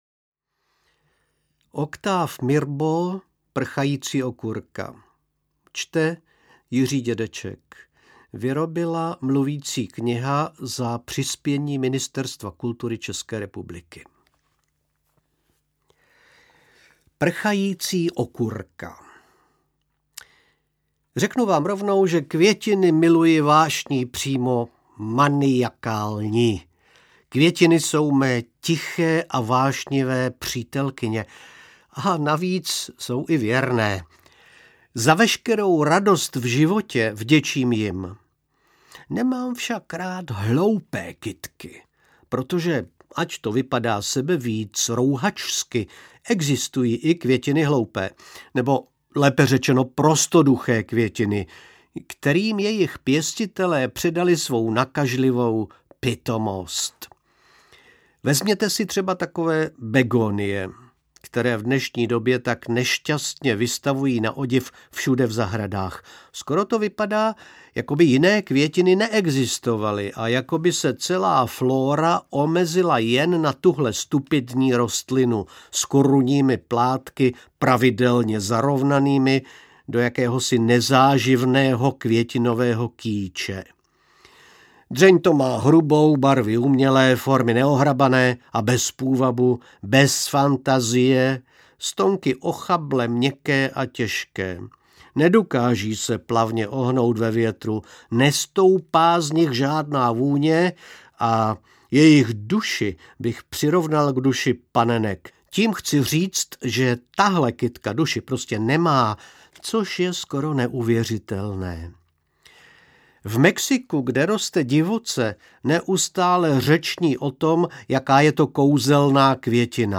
Čte: Jiří Dědeček